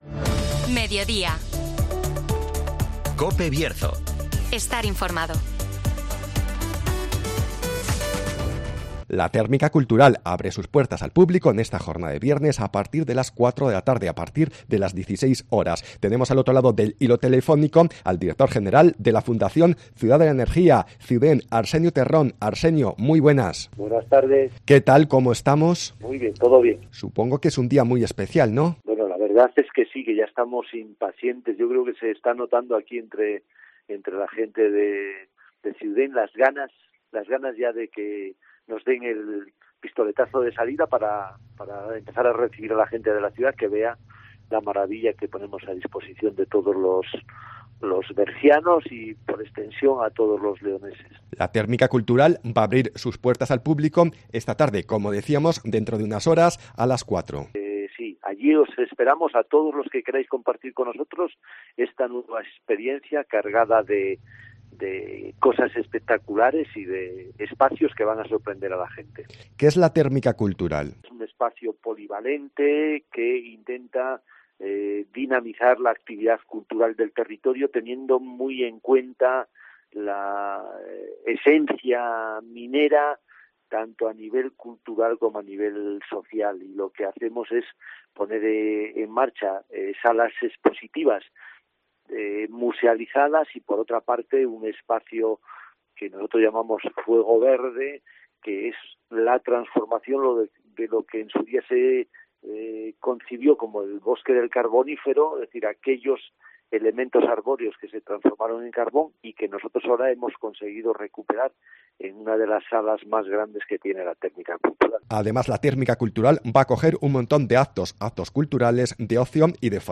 SOCIEDAD-MEDIODÍA COPE